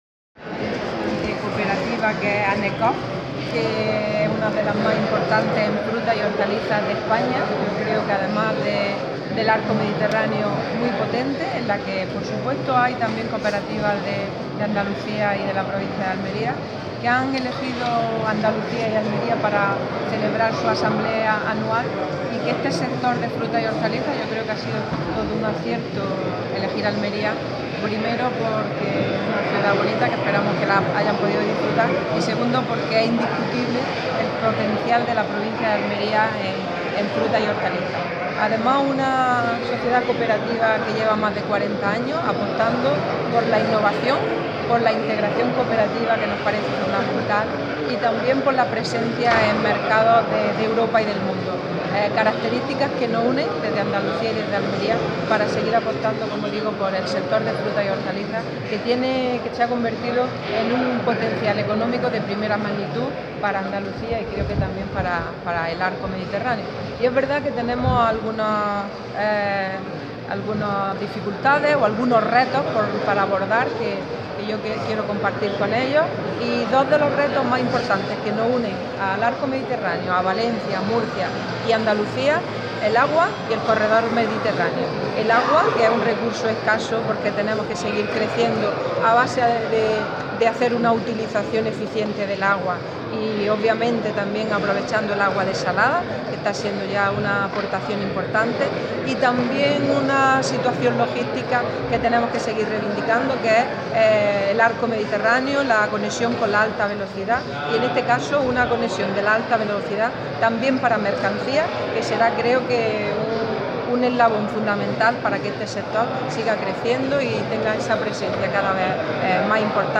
La consejera, durante su intervención en la Asamblea General de Anecoop.
Declaraciones consejera Anecoop